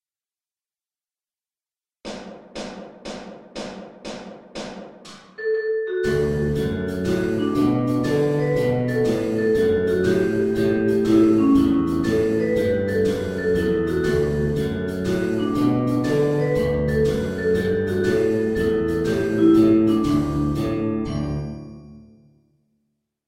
"He's Got the Whole World in His Hands" melody track at a faster tempo